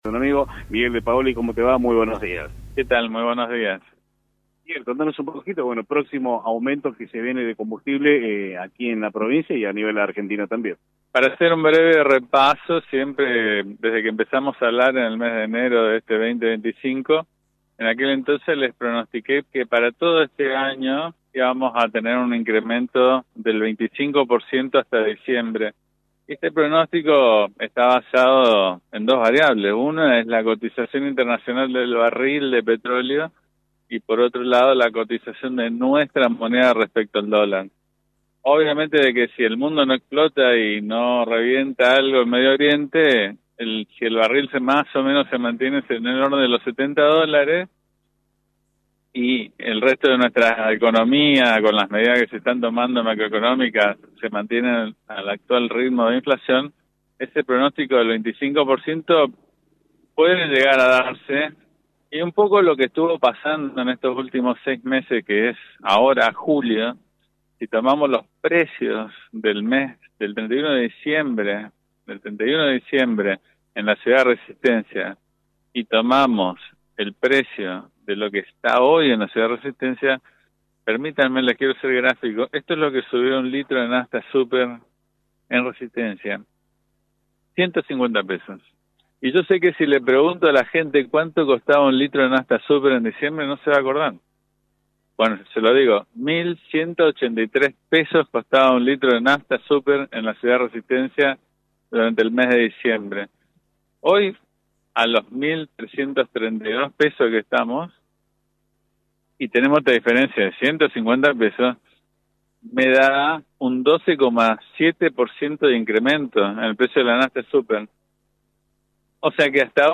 En otro tramo de la entrevista, el empresario explicó que todas las estaciones de servicio deben aprobar la Verificación Técnica Obligatoria, que inspecciona el estado de surtidores, tanques, válvulas y otras instalaciones críticas.